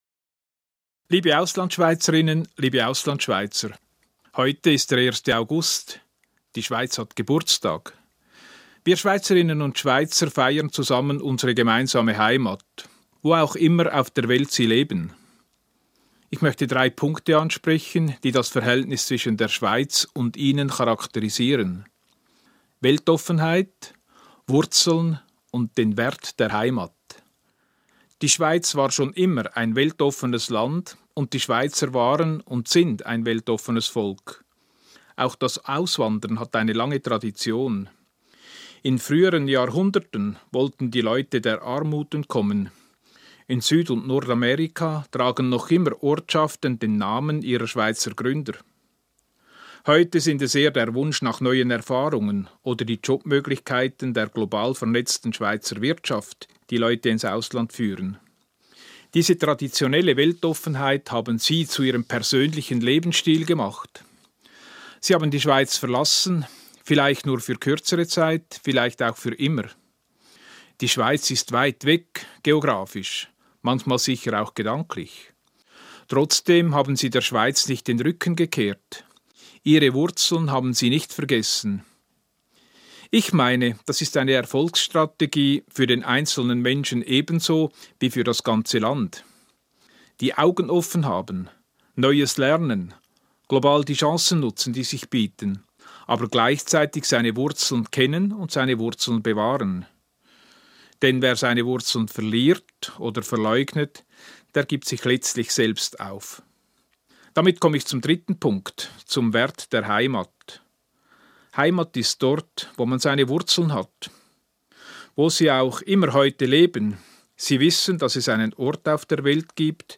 Öffnung gegenüber der Welt, Besinnung auf die eigenen Wurzeln und die Werte der Schweiz: Dies sind drei Punkte, die der Schweizer Bundespräsident Ueli Maurer ins Zentrum seiner 1.-Augustrede für die Schweizer im Ausland rückt. Hören Sie hier seine Ansprache.